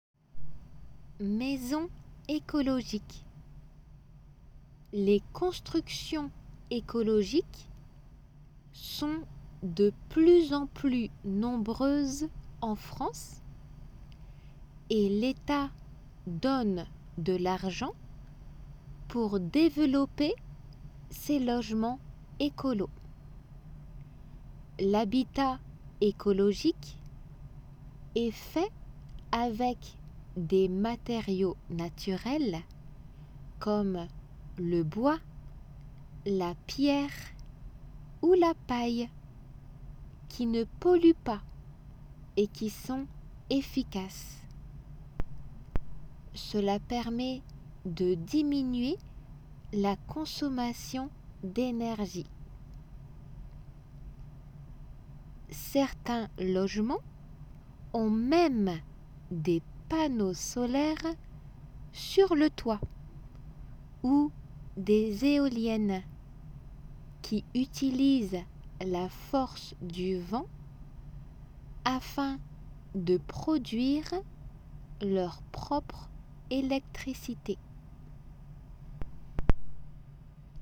仏検2級11月に向けて準備 8ー書き取り音声
普通の速さで